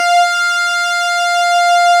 Added synth instrument
snes_synth_065.wav